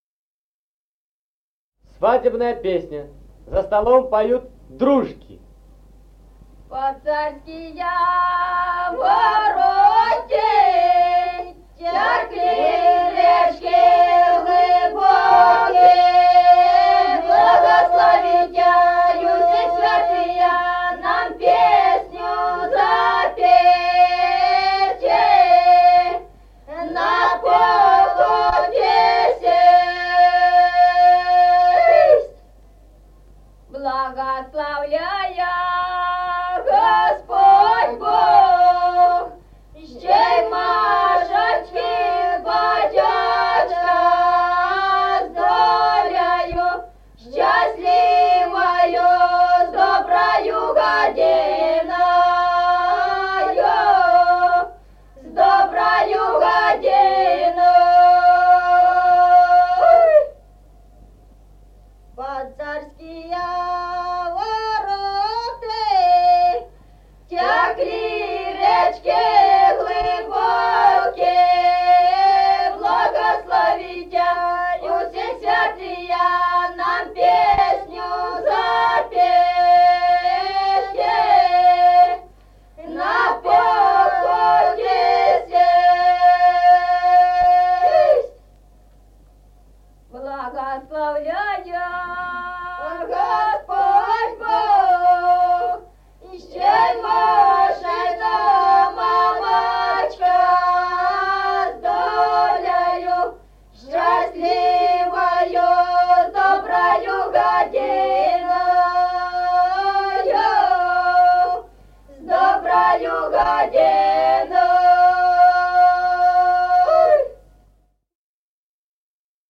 Народные песни Стародубского района «Под царские вороты», свадебная, за столом поют дру́жки.
подголосник
запев